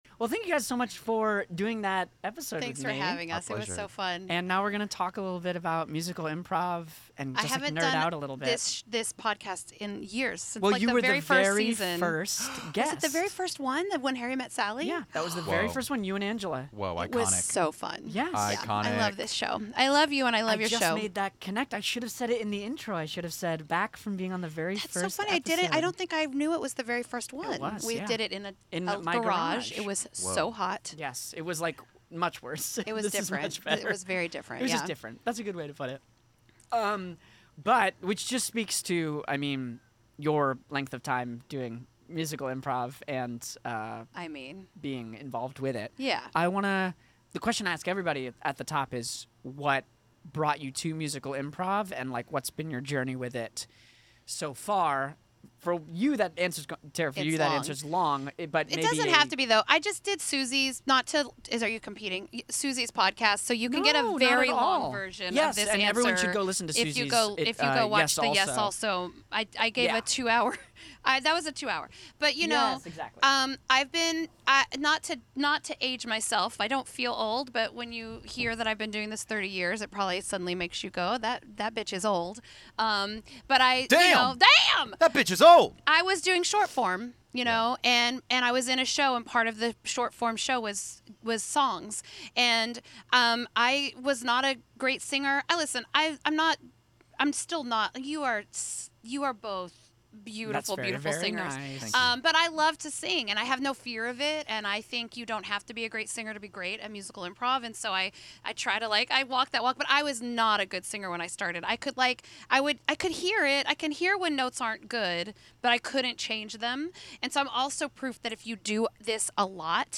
A deep-dive musical improv chat with hindsight analysis of of the Blackberry Time Machine episode!